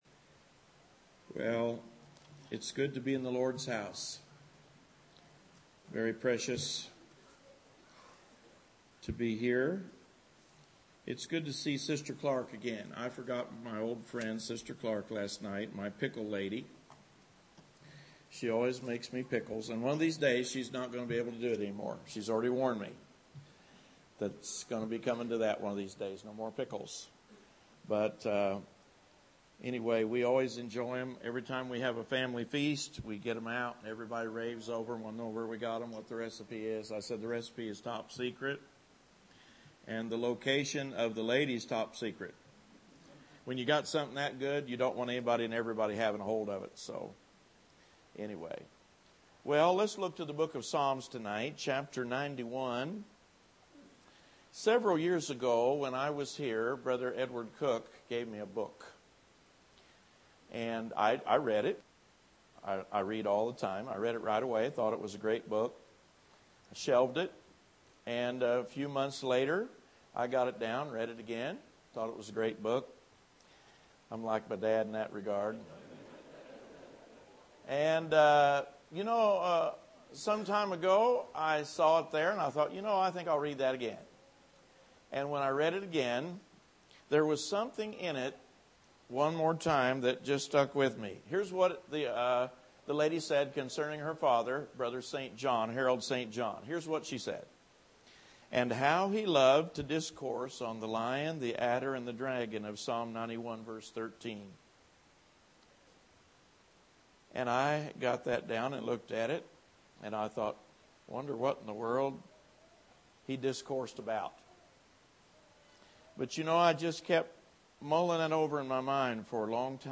Series: Spring Revival 2015 Tagged with adder , bitterness , dragon , lion , overcome , worry